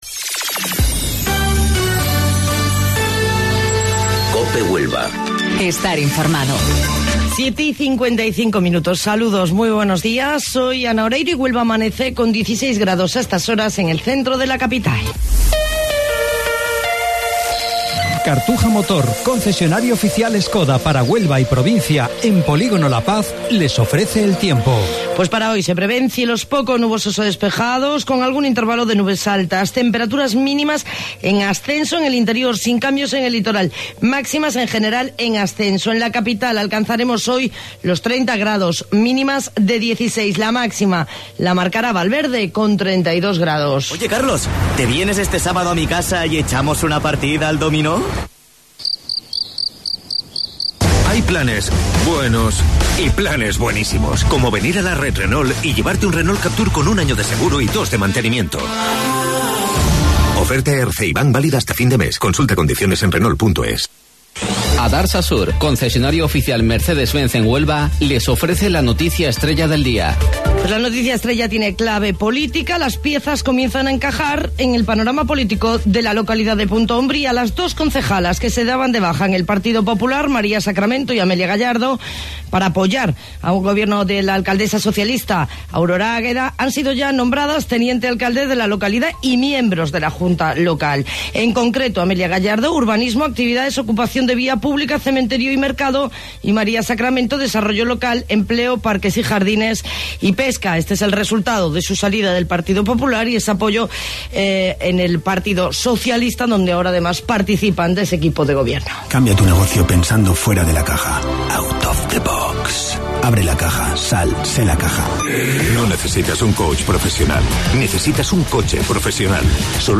AUDIO: Informativo Local 07:55 del 21 de Junio